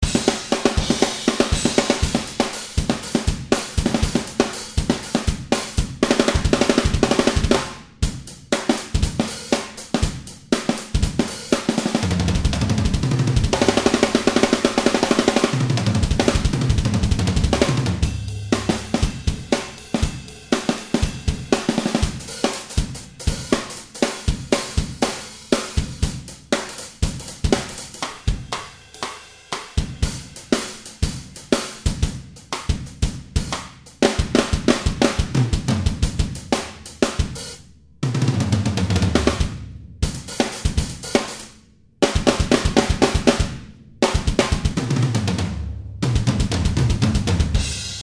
- En collant les patterns ( piochés au pif en quelques secondes dans la liste qui en comporte plusieurs centaines ( comme sur la photo 2 ) et collés aléatoirement en quelques secondes aussi juste pour donner une idée du son ) =